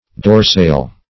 Dorsale \Dor"sale\, n.